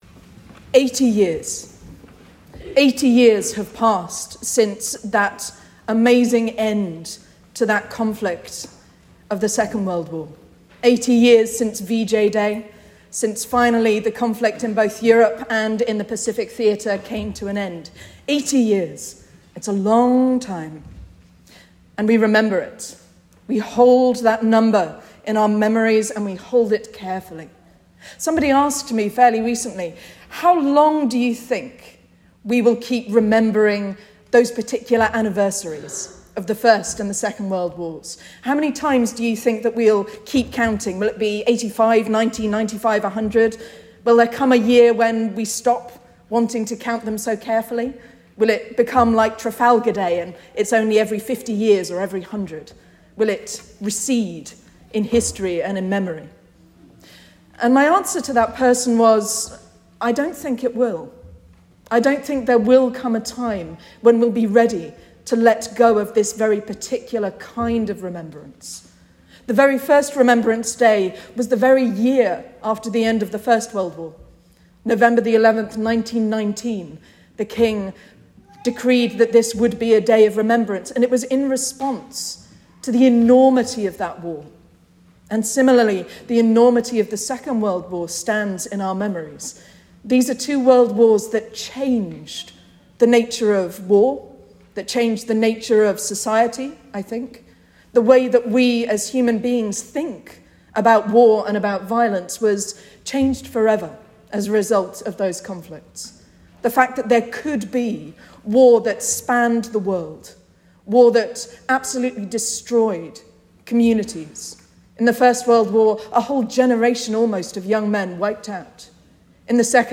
Sermon and Reading for Remembrance Sunday 9th November 2025